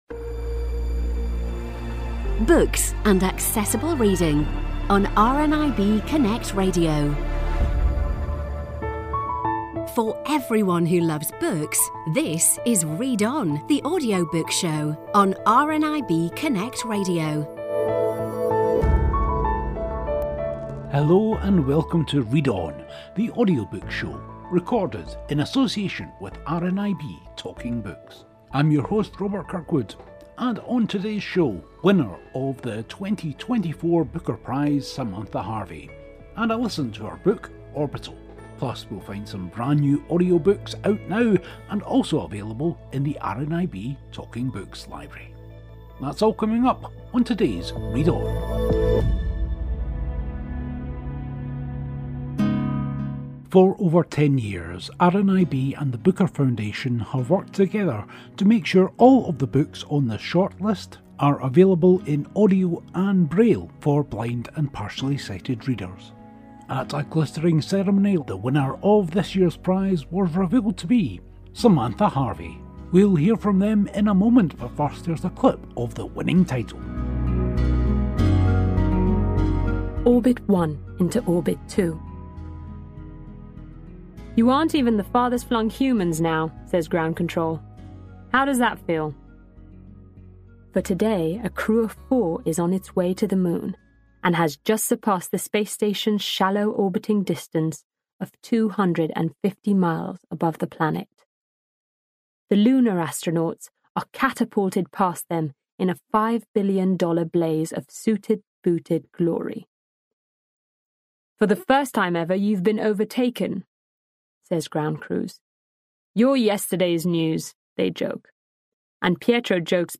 Today a long chat with Booker Winner 2024 Samantha Harvey and a listen to her winning book Orbital.